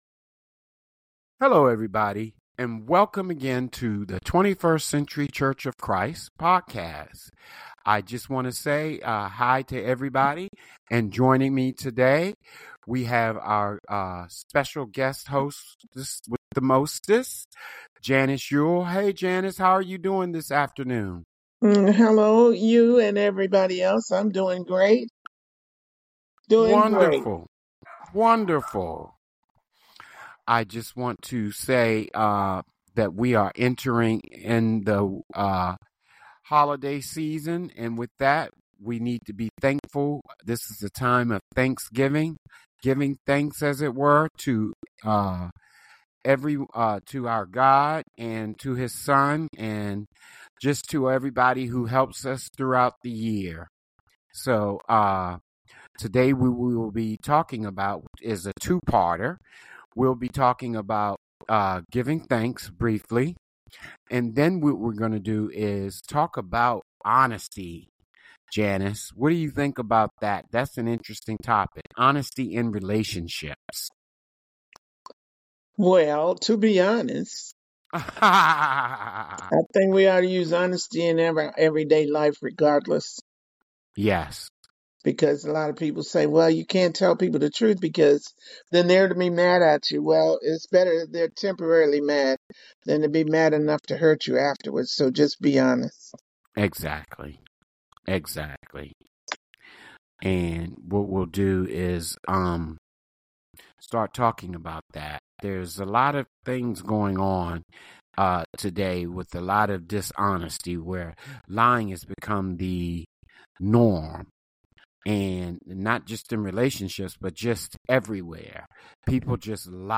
Sermons | 21st Century Church of Christ